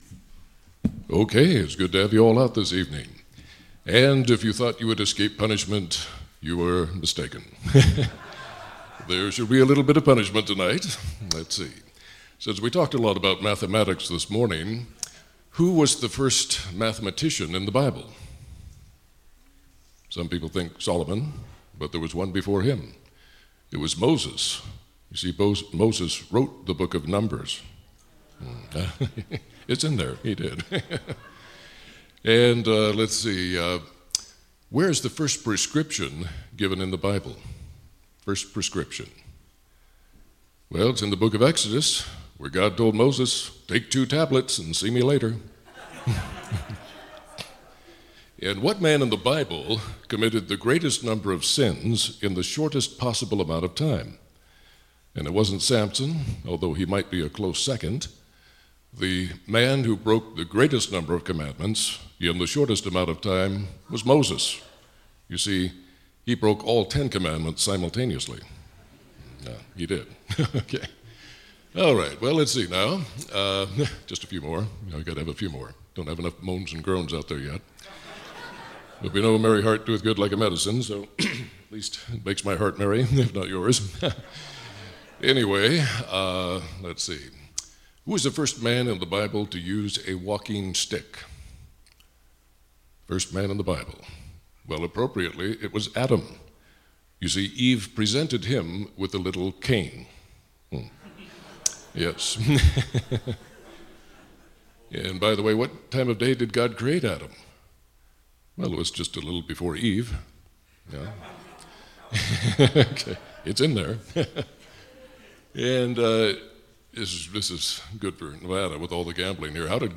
September 20, 2020 – Evening Service